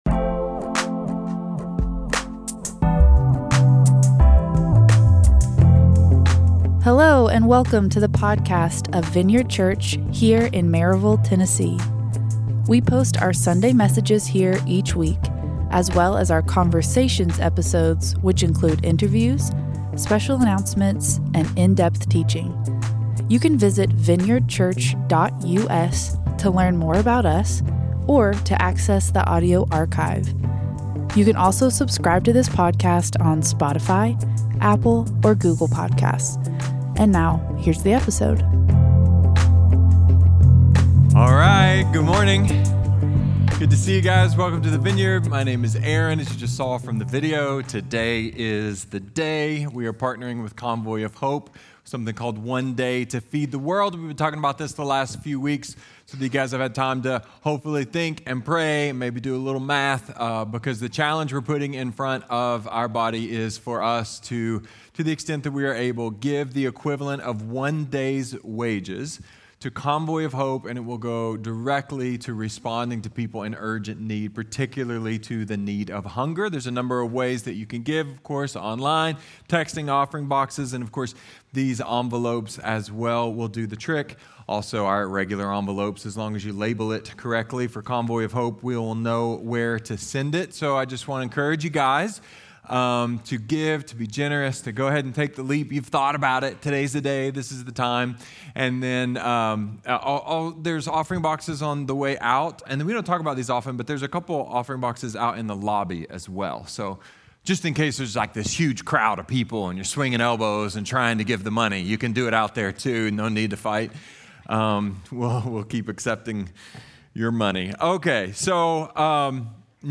A message from the series "Prodigal."